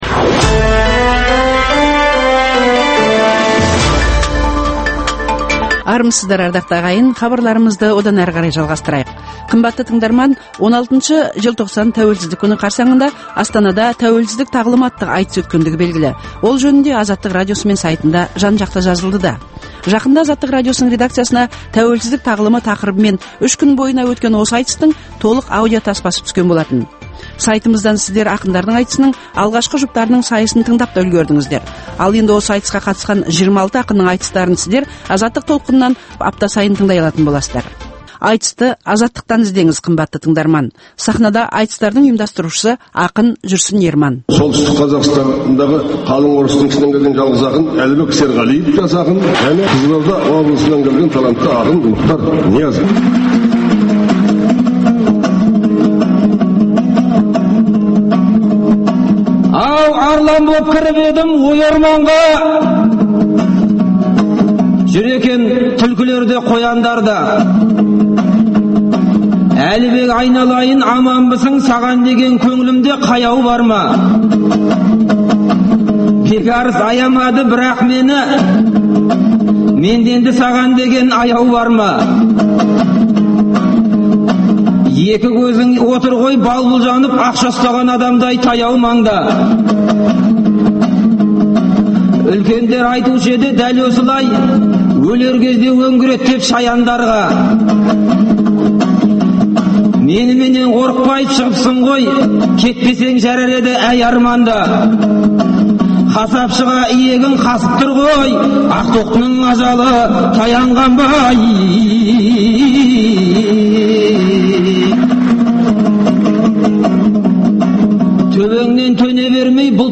Айтыс - Азаттықта